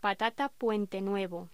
Locución: Patata puente nuevo
voz
Sonidos: Hostelería